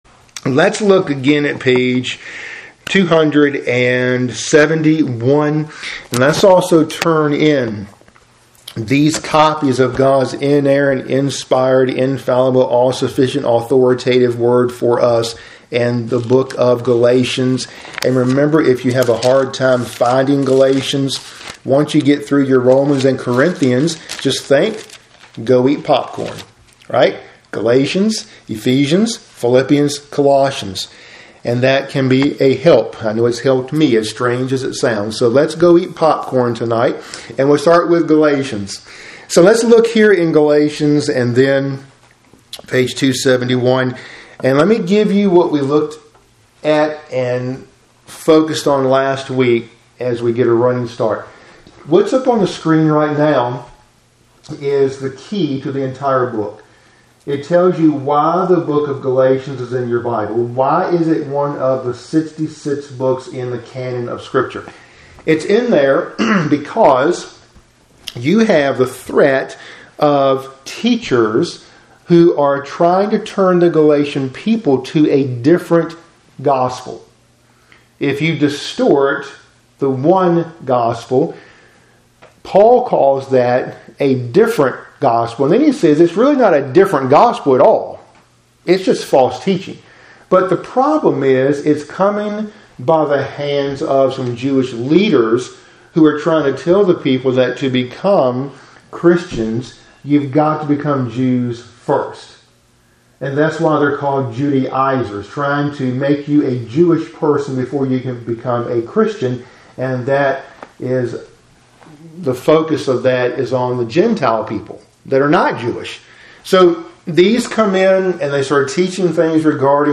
Midweek Bible Study – Lesson 55 (cont.)